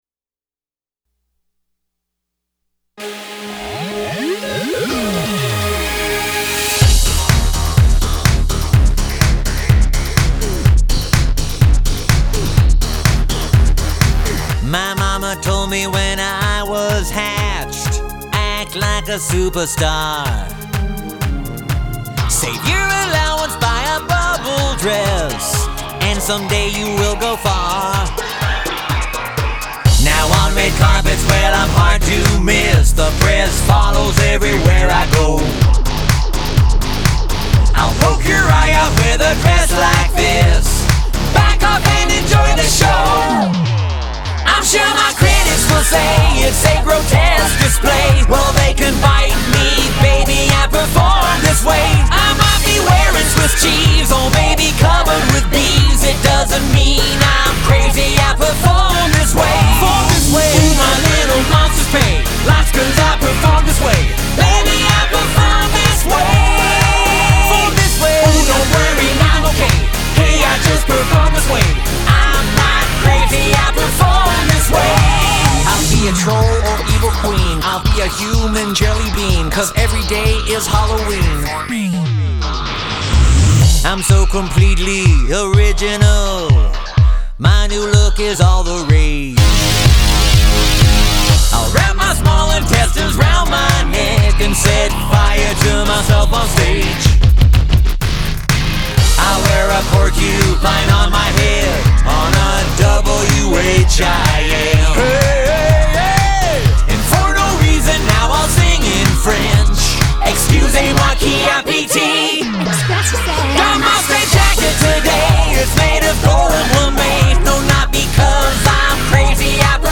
Recently in the studio recording background vocals